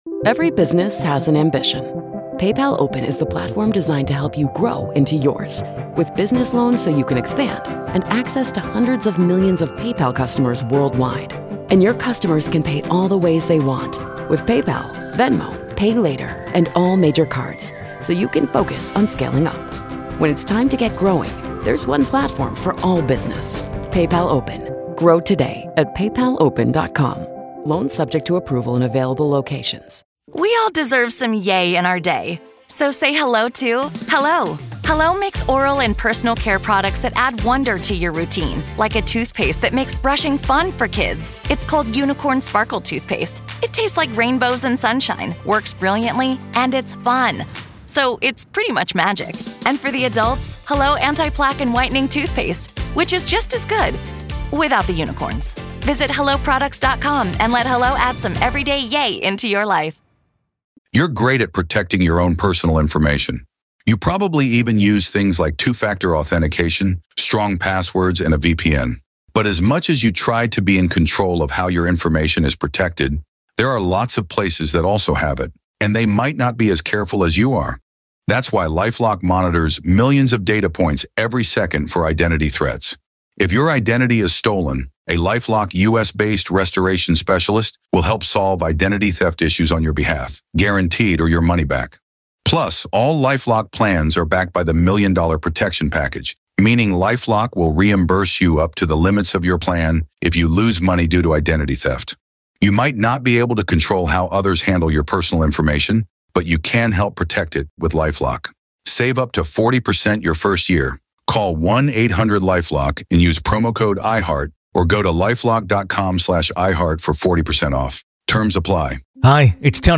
Real Ghost Stories Online